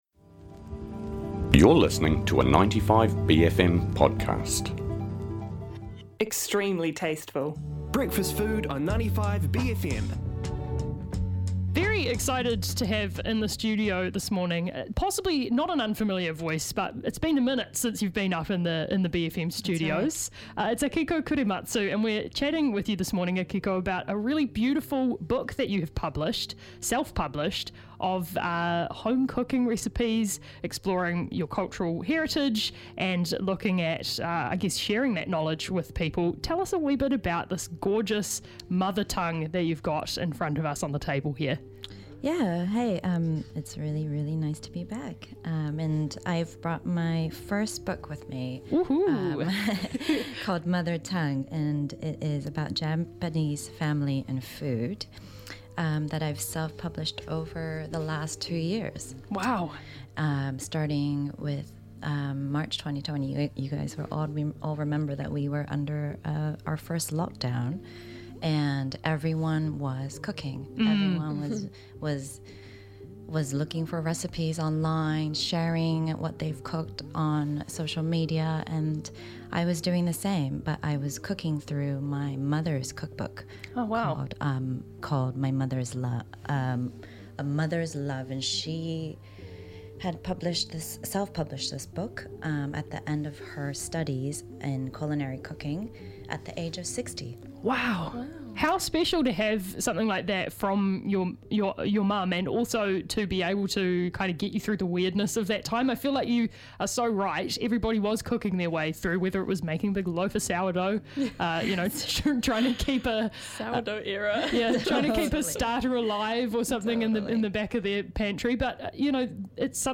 comes into the studio to talk about Mother Tongue, an intergenerational cookbook which asks you to consider cooking using local produce, and shop from small businesses who practice sustainable food sourcing.